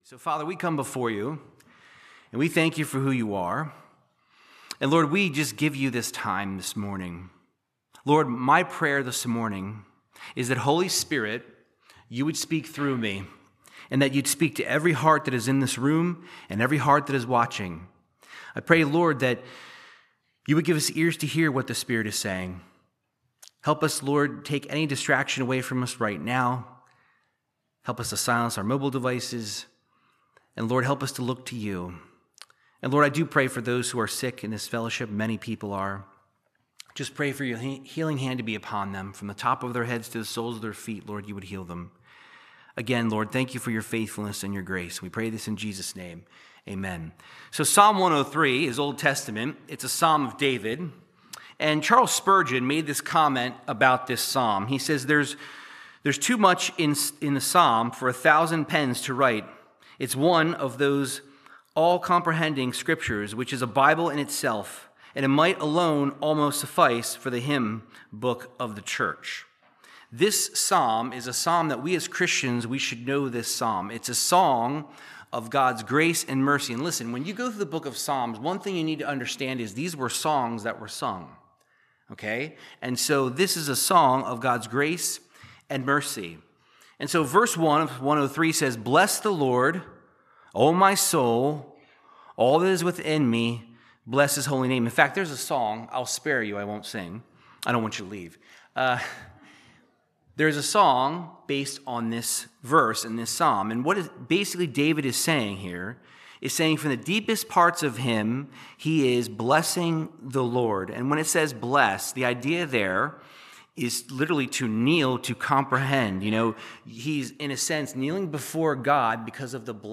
Verse by verse Bible teaching through the book of Psalms chapter 103